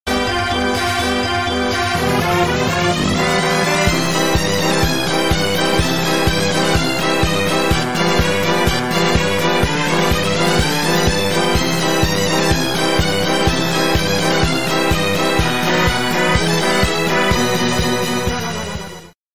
Звуки и музыка цирковая в разных вариантах.
23. Музыка клоуна из цирка для монтажа видео